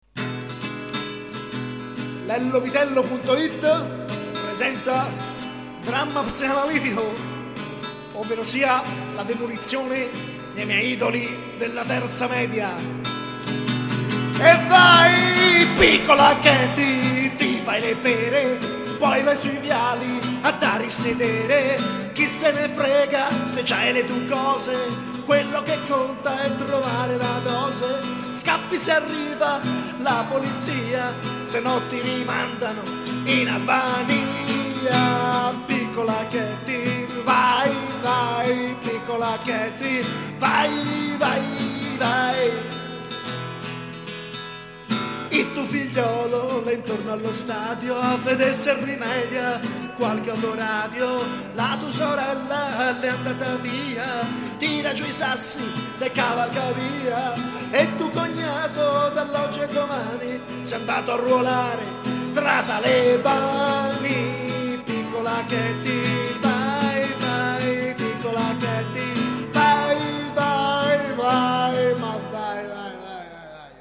Un delirio improvvisato